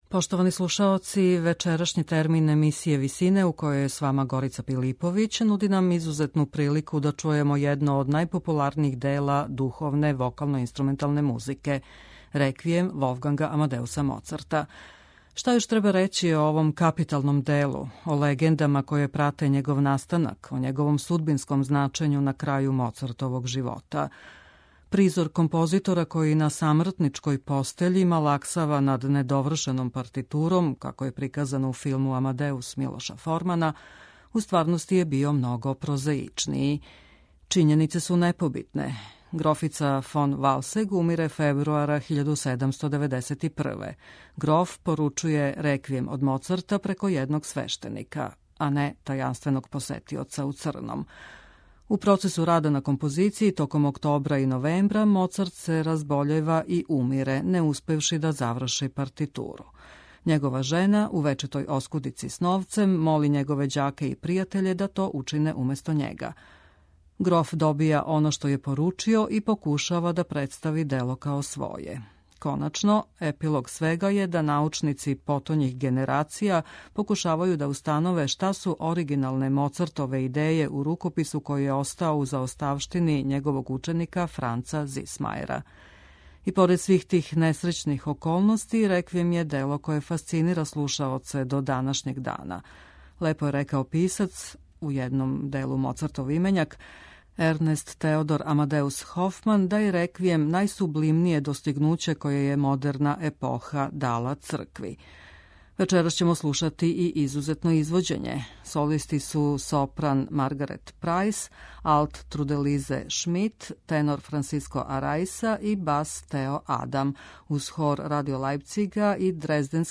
У вечерашњој емисији Висине од 18.55 можете слушати капитално остварење вокално-инструменталне црквене музике